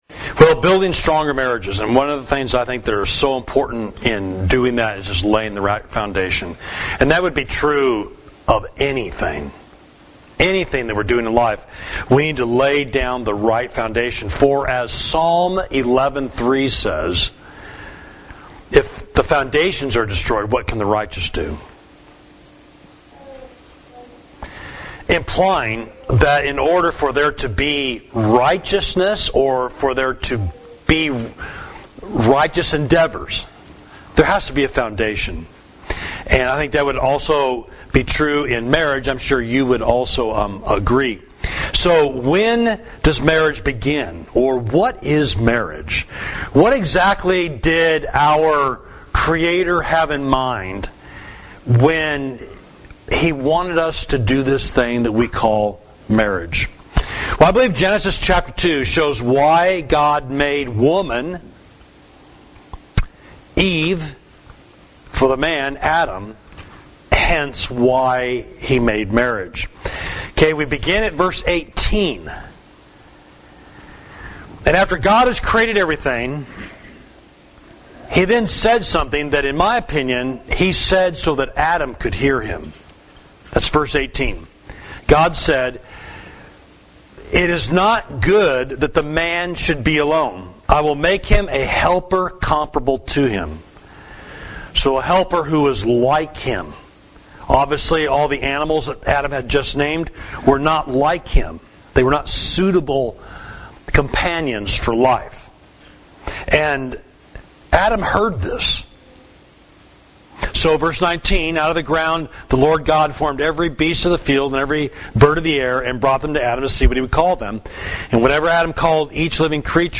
Sermon: Building Stronger Marriages #2: Understanding the Crea